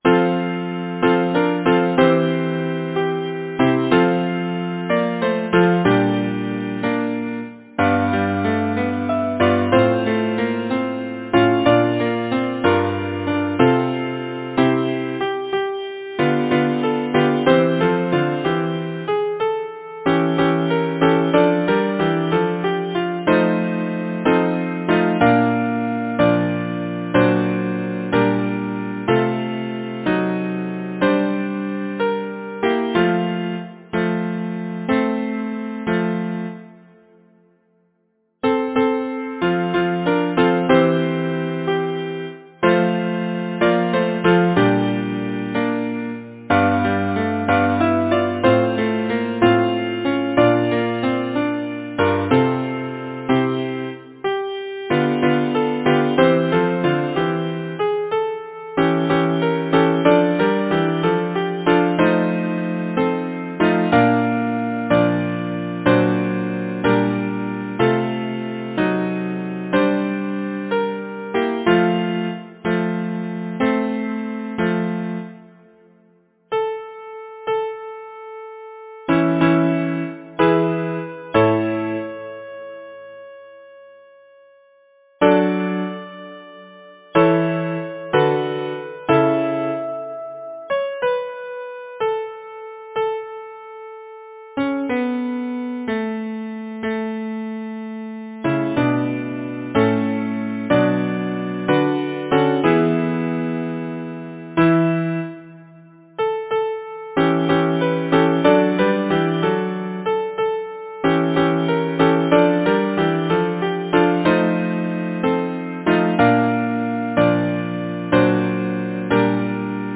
Title: Love wakes and sleeps Composer: George Alexander Macfarren Lyricist: Walter Scott Number of voices: 4vv Voicing: SATB Genre: Secular, Partsong
Language: English Instruments: A cappella